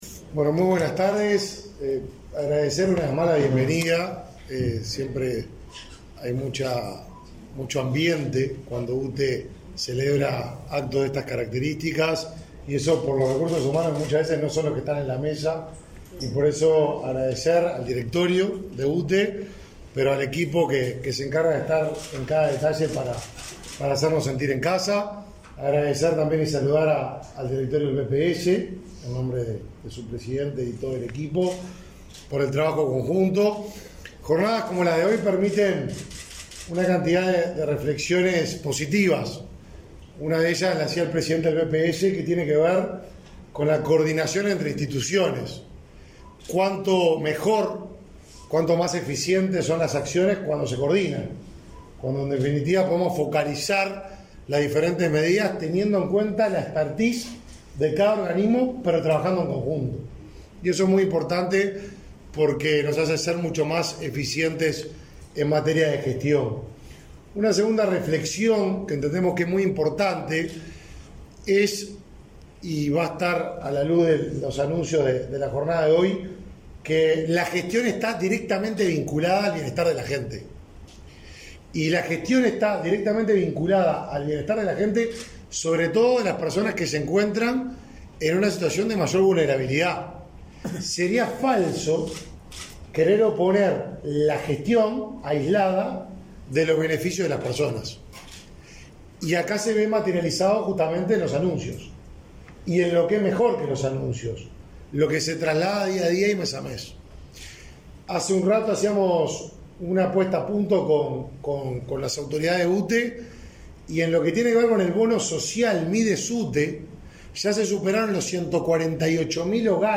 Conferencia de prensa por beneficios para el Programa de Asistencia a la Vejez
Participaron del evento el ministro de Desarrollo Social, Martín Lema; la presidenta de UTE, Silvia Emaldi, y el presidente de BPS, Alfredo Cabrera.